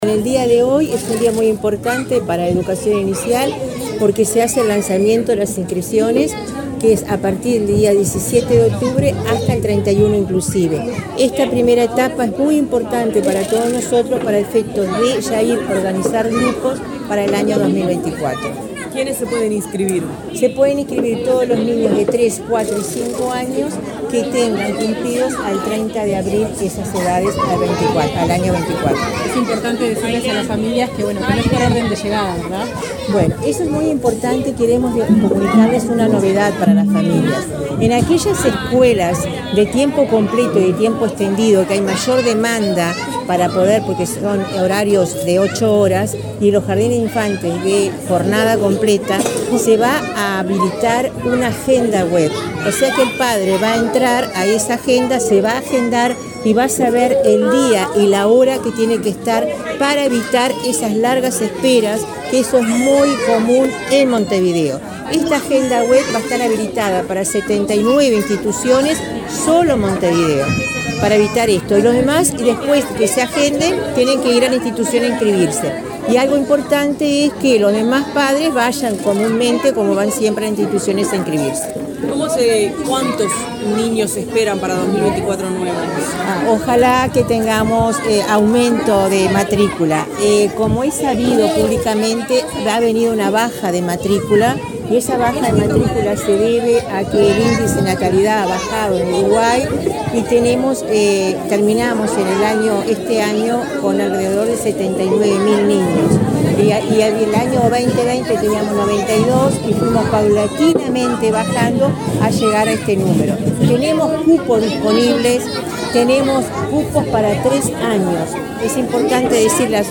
Declaraciones de la directora general de Educación Inicial y Primaria